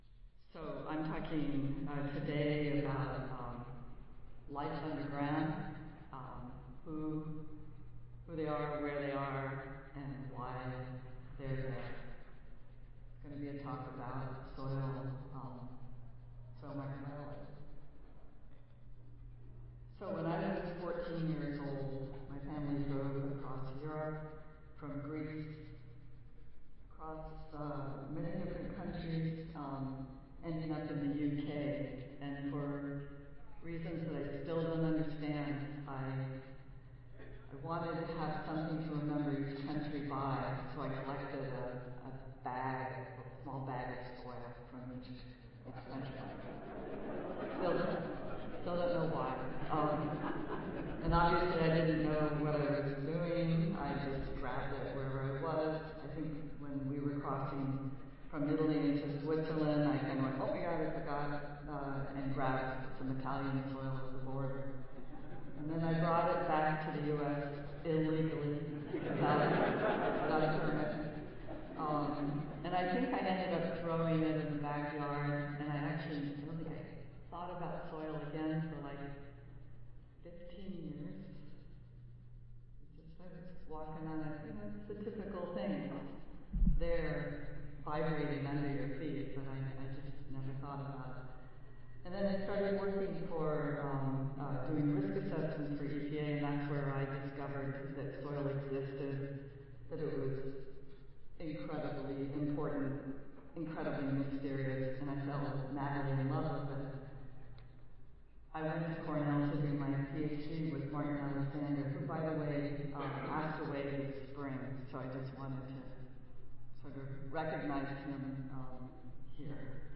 Marriott Tampa Waterside, Grand Ballroom E and F
University of California-Davis Audio File Recorded Presentation 9:00 AM Adjourn << Previous Session